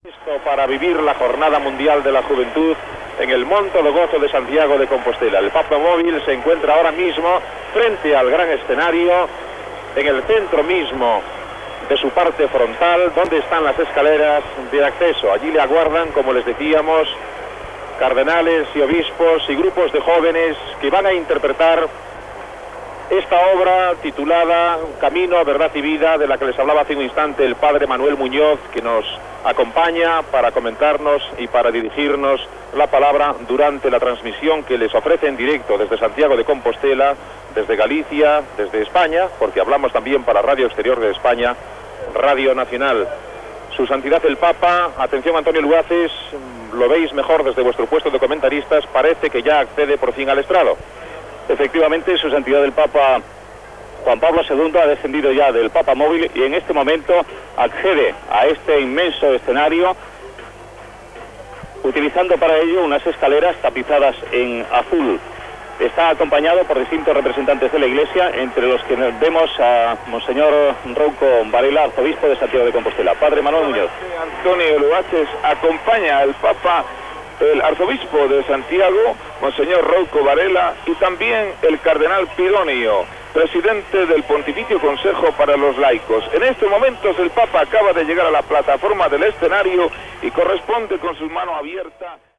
Transmissió de l'arribada del Sant Pare Joan Pau II a la IV Jornada Mundial de la Joventut, a Santiago de Compostel·la
Informatiu